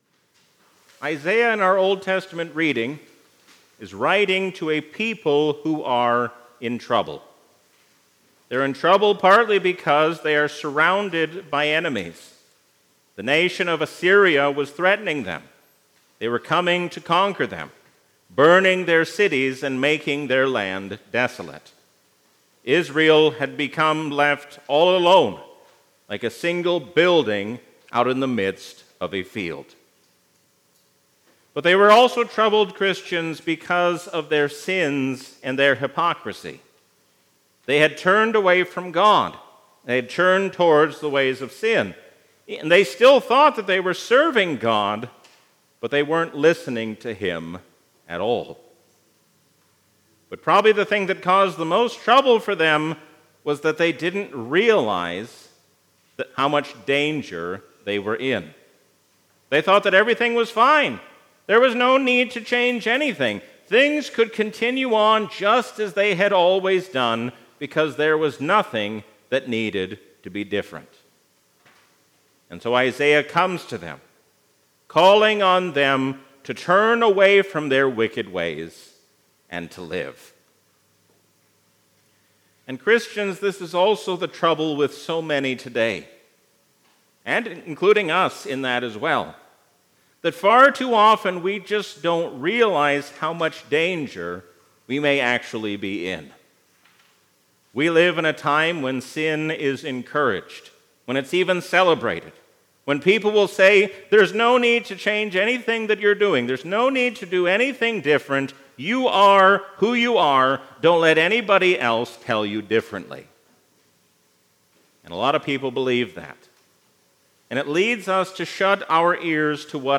Sermon
A sermon from the season "Trinity 2024."